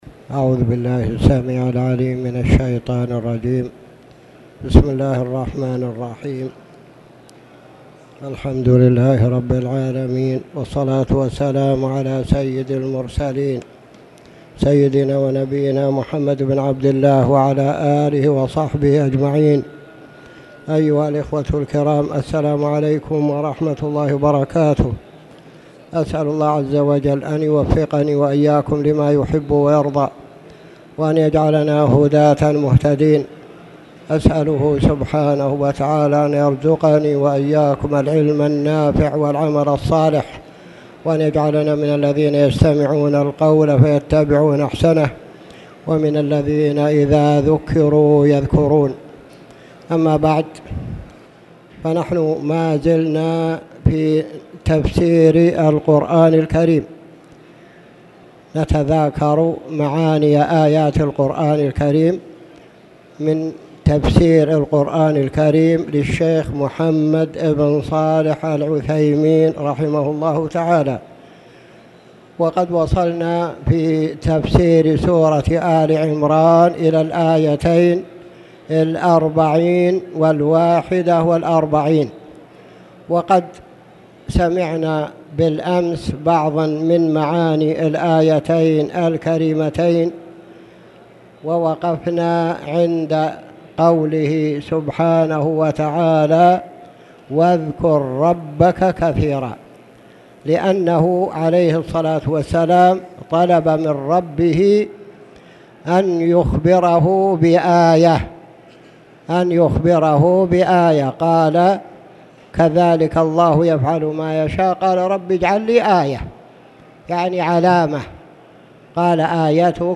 تاريخ النشر ١٧ جمادى الأولى ١٤٣٨ هـ المكان: المسجد الحرام الشيخ